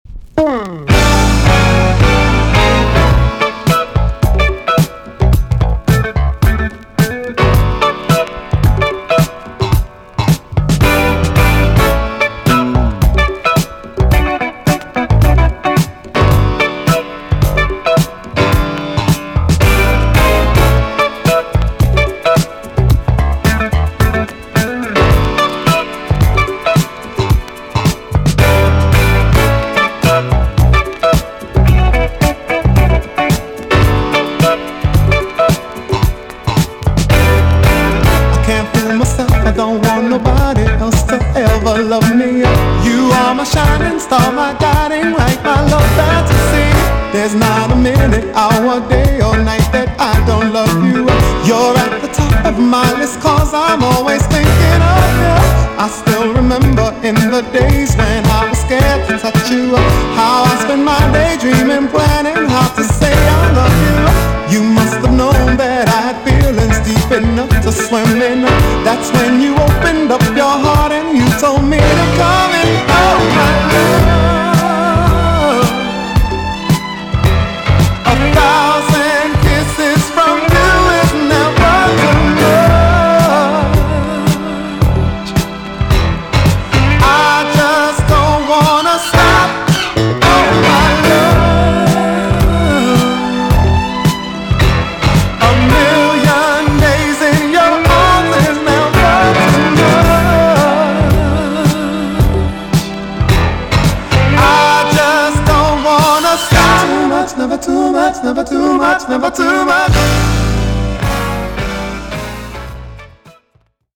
VG+~VG ok 前半は良好ですが後半キズの箇所がありノイズが入ります。
1981 , JAMAICAN SOUL RECOMMEND!!